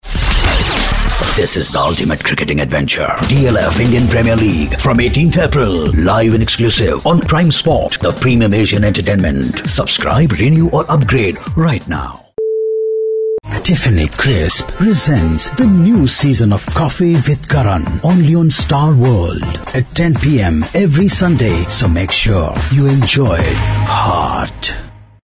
Hindi, Urdu, Indian English, friendly, warm, sexy, dramatic, expressive, versatile, can traslate into Hindi and Urdu, can write concept & scripts, can mix audio also
Sprechprobe: Werbung (Muttersprache):
Voice adaptable to any genre.